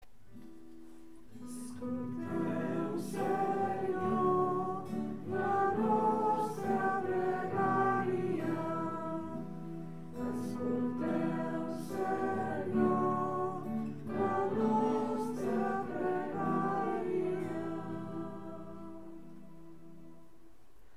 Pregària de Taizé
Capella dels Salesians - Diumenge 25 de maig de 2014